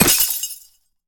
ice_spell_impact_shatter_05.wav